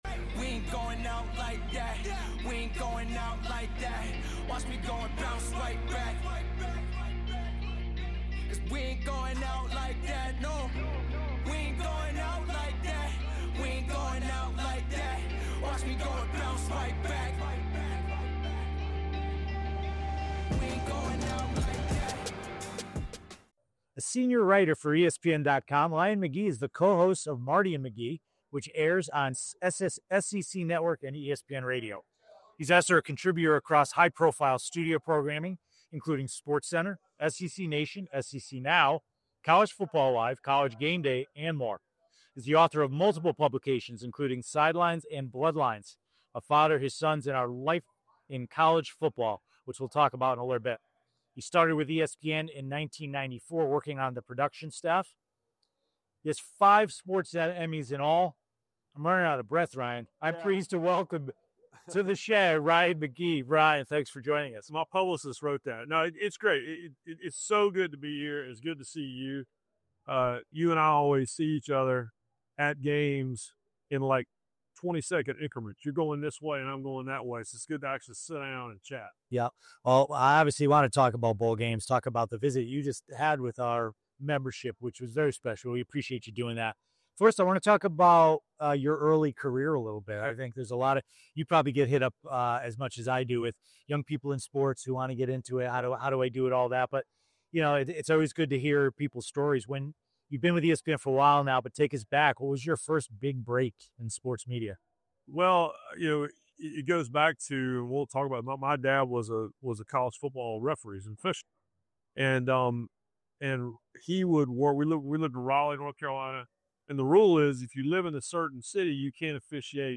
May 27, 2025 In this special episode recorded live from the 2025 Bowl Season Annual Meeting in Las Vegas, we sit down with ESPN's Ryan McGee—award-winning journalist, storyteller, and co-host of Marty & McGee. From growing up in the South to becoming one of college football's most respected voices, Ryan shares the story of his unconventional path into sports media and the lessons he's learned along the way. We dove deep into the state of college football's postseason, discussing how the sport is evolving in the age of expanded playoffs, NIL, and shifting fan expectations.